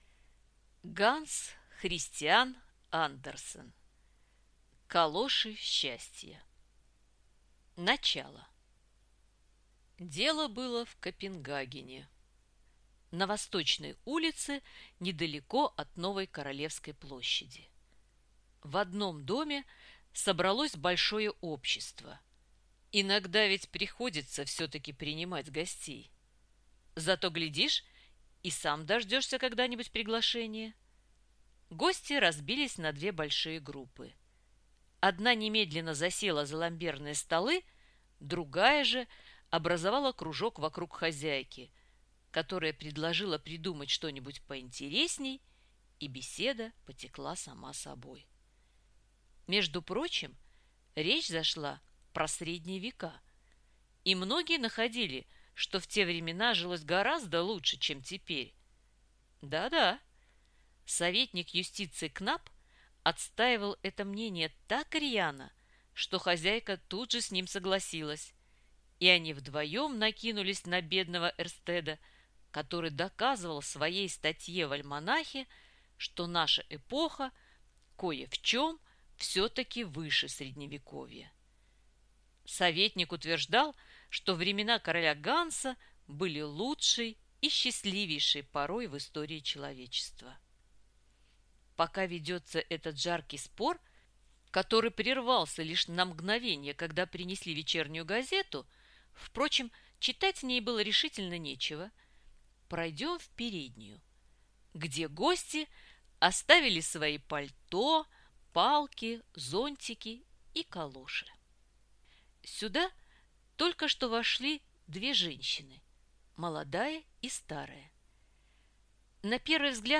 Аудиокнига Калоши счастья | Библиотека аудиокниг